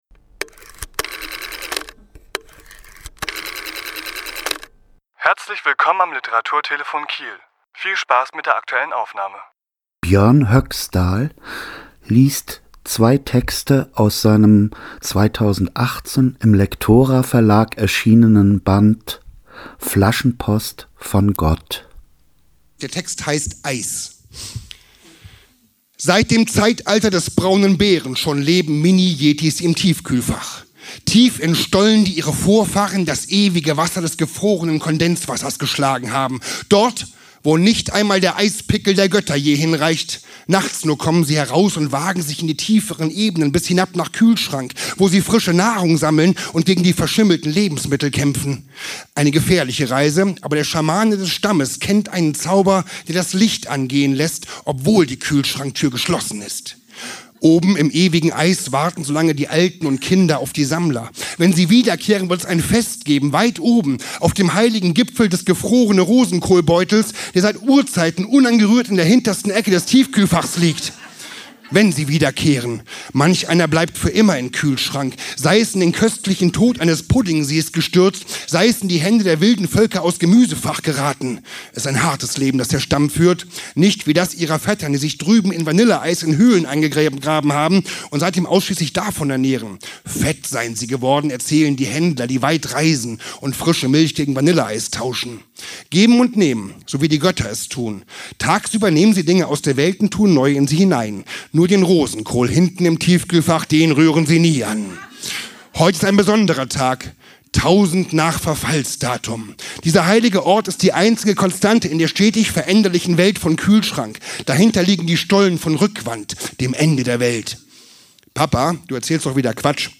Autor*innen lesen aus ihren Werken
Die Aufnahmen entstanden bei Live-Auftritten.